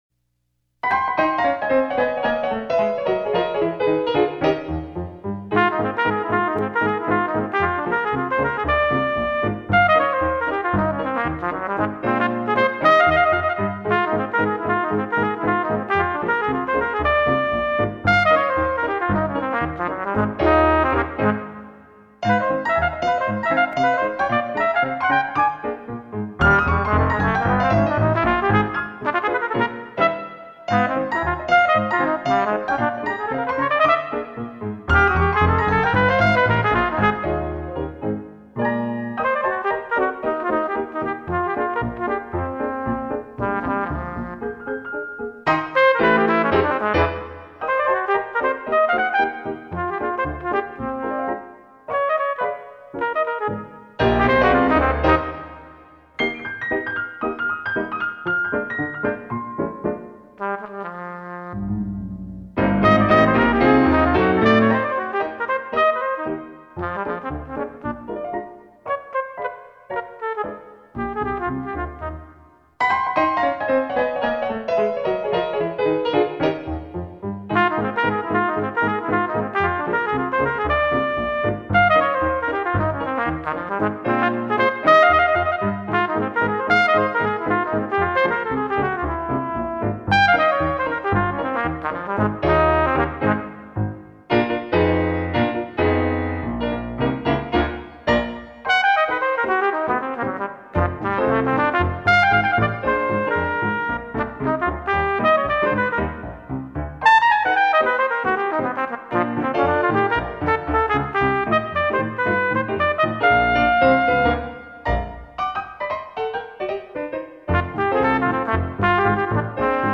This great American rag sounds terrific on trumpet.